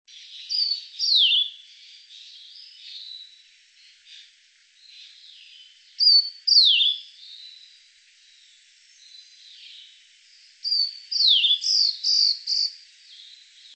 16-4溪頭藪鳥duet1.mp3
黃痣藪鶥 Liocichla steerii
南投縣 鹿谷鄉 溪頭
錄音環境 森林
行為描述 二重唱